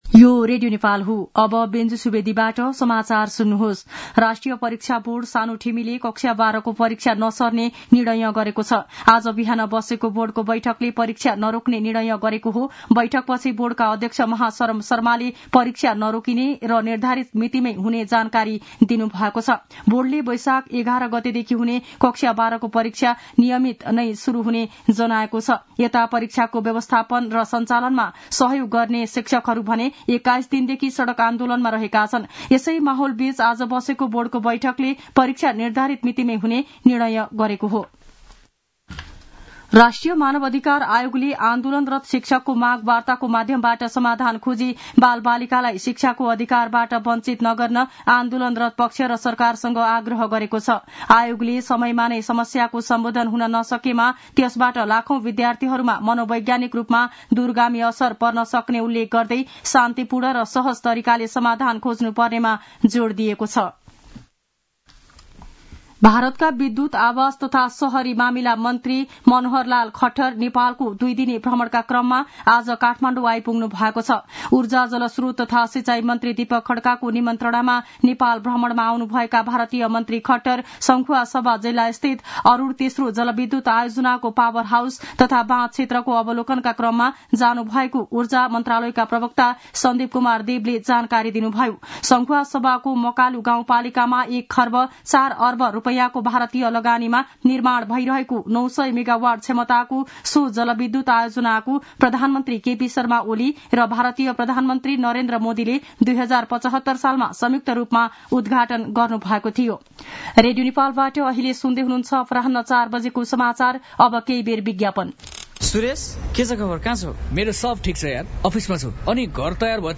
दिउँसो ४ बजेको नेपाली समाचार : ९ वैशाख , २०८२
4-pm-news-1-7.mp3